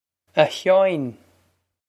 Pronunciation for how to say
Ah Hyaw-in
This is an approximate phonetic pronunciation of the phrase.